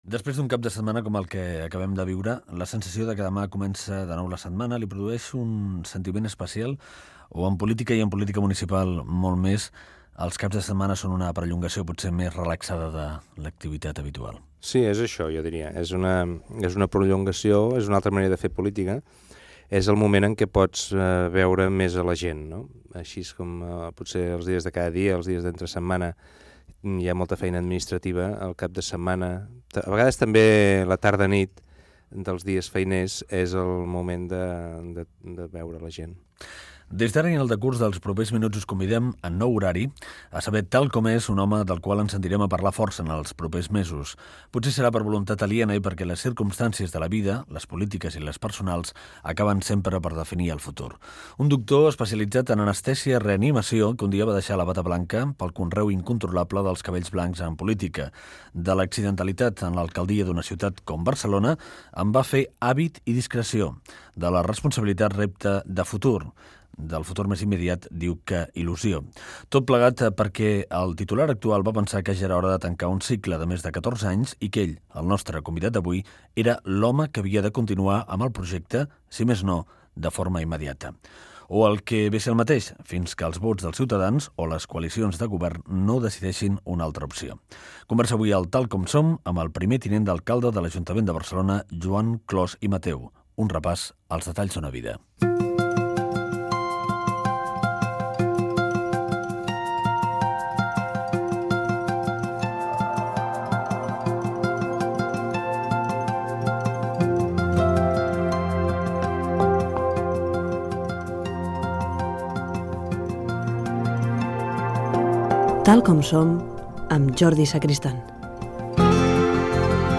Fragment d'una entrevista al polític Joan Clos, primer tinent d'alcalde de l'ajuntament de Barcelona.